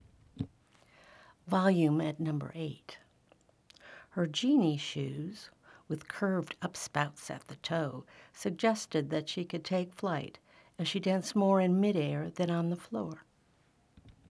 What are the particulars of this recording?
I have built my little bathroom blanket sound studio and have some samples. I’ve recorded the same line at four different volume levels.